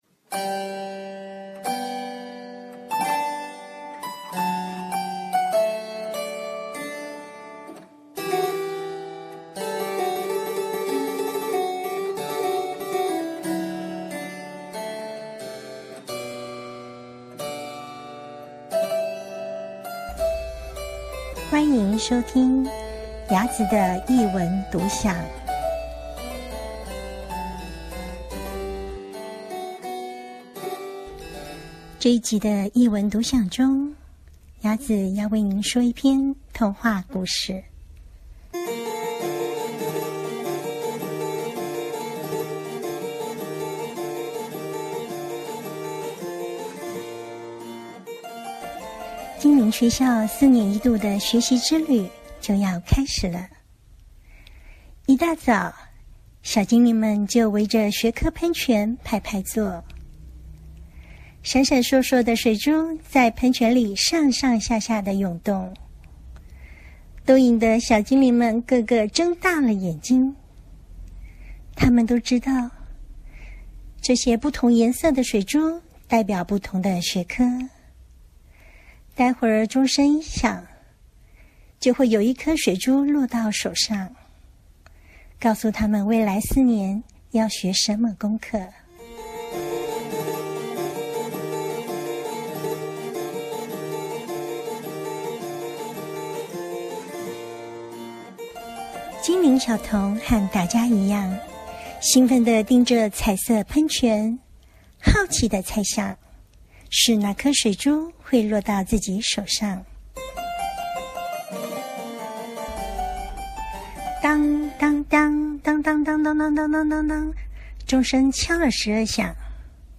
林世仁著 國語日報出版 背景音樂